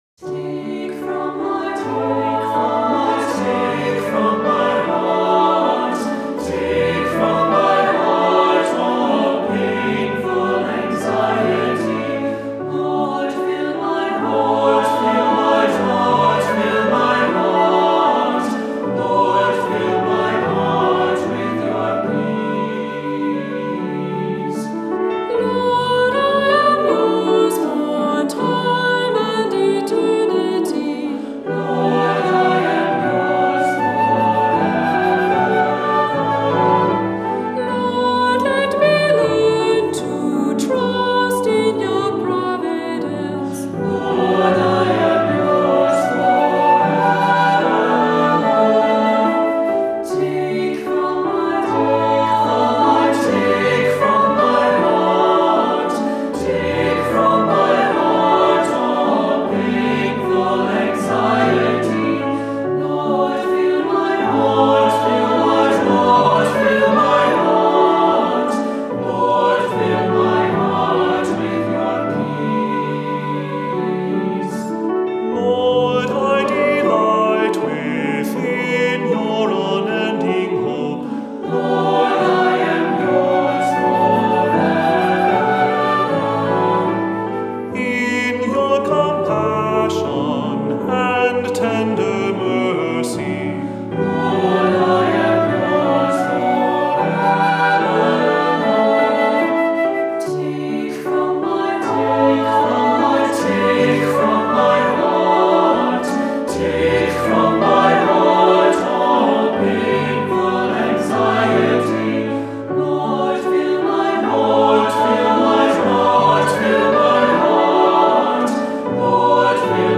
Voicing: SATB, cantor, assembly